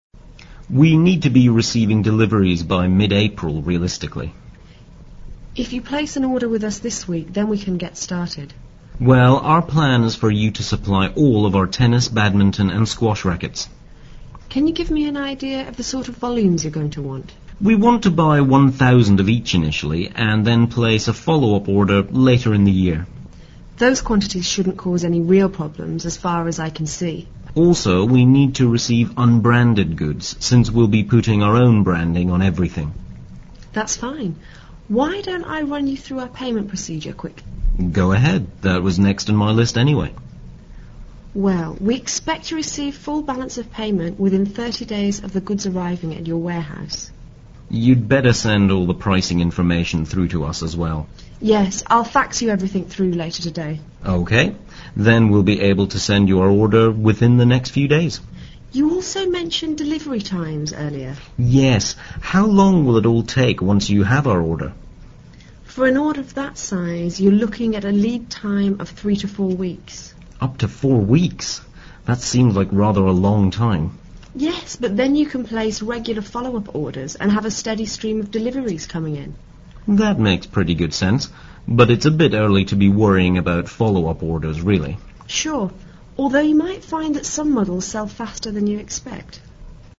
Listen to the dialogue between HaiSports Buyer (HB) and Supplier(s)